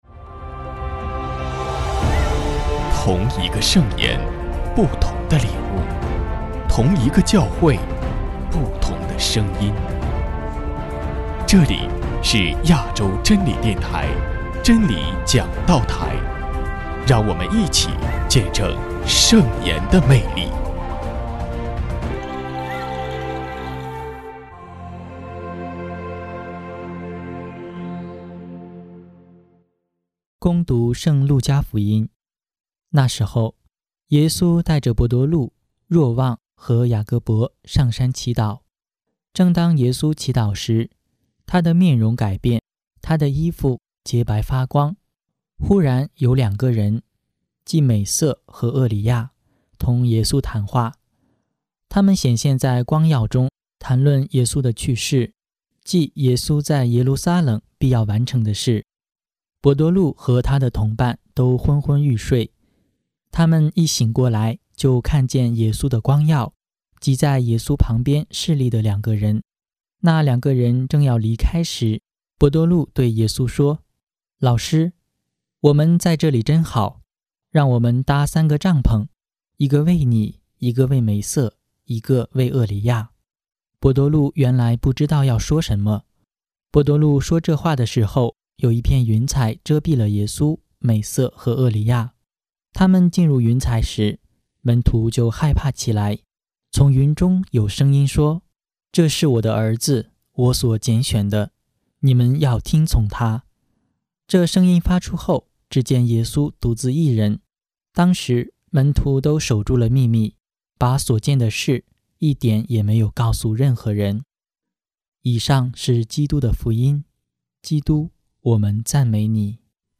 【真理讲道台 】63|四旬期第二主日证道